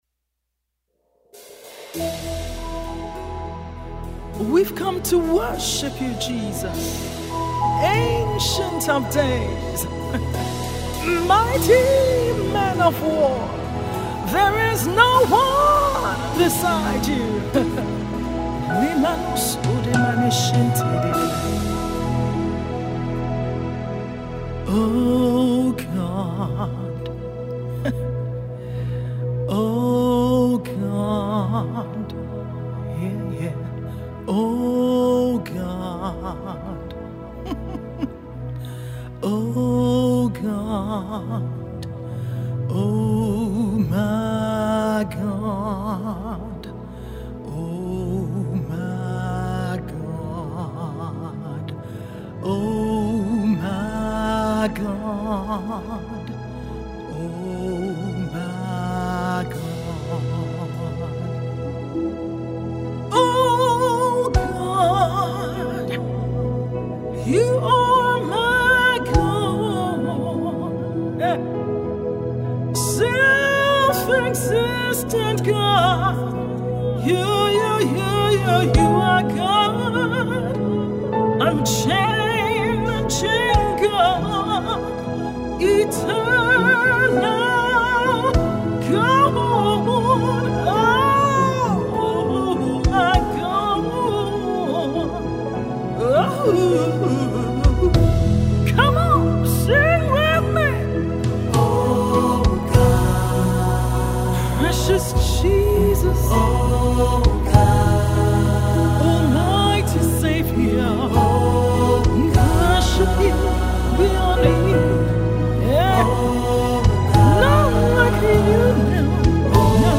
Abuja based gospel recording artiste